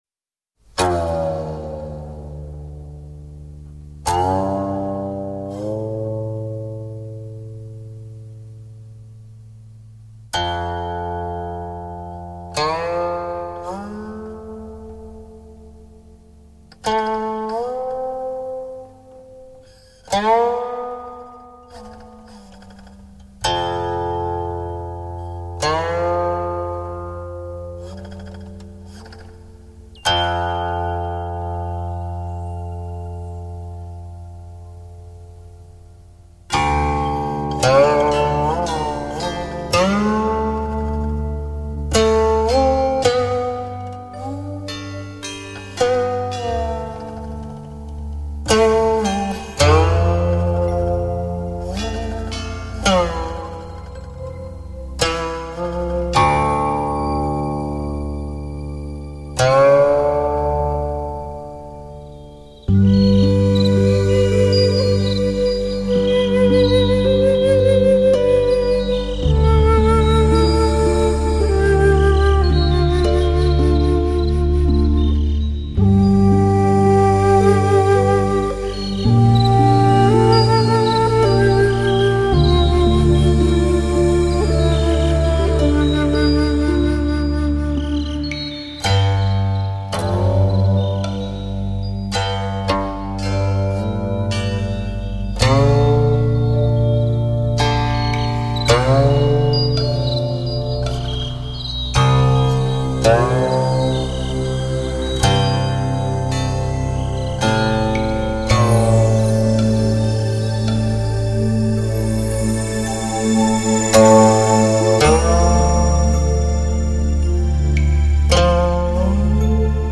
稀世古琴，跨越千年，体会悠悠禅机密境